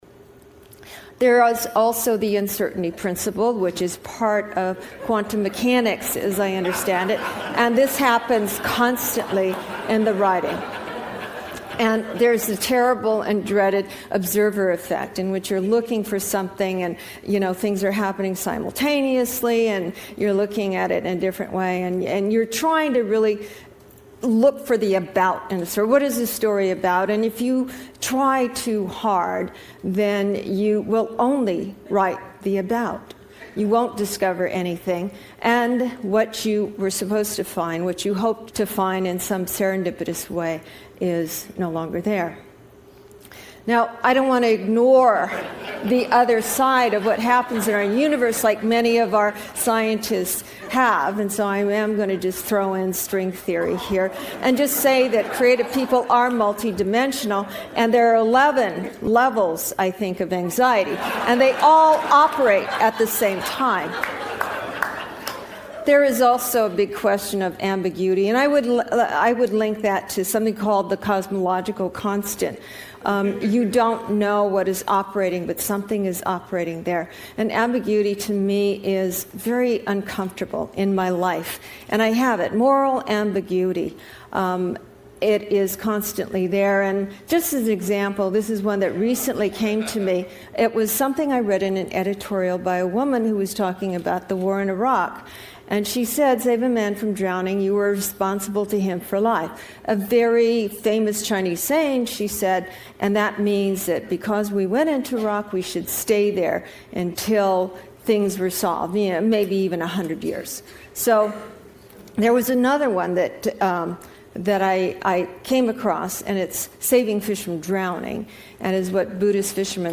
TED演讲:创意隐藏在哪里(5) 听力文件下载—在线英语听力室